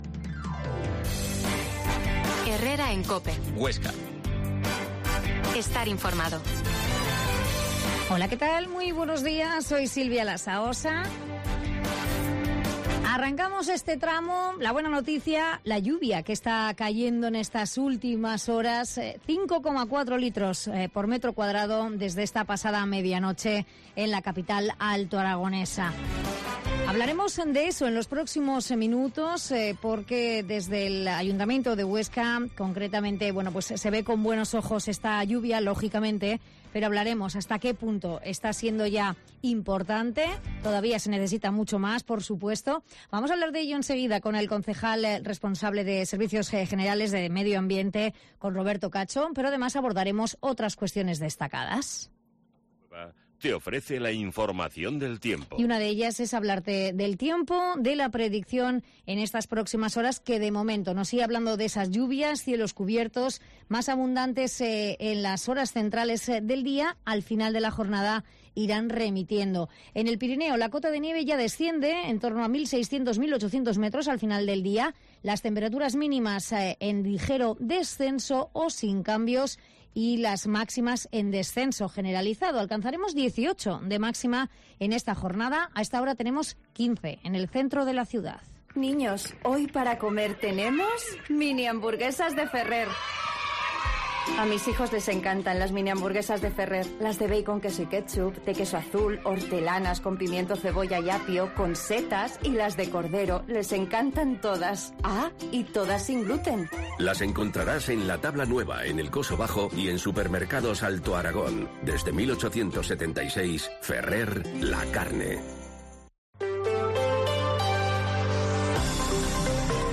Herrera en COPE Huesca 12.50h Entrevista al concejal de servicios generales, Roberto Cacho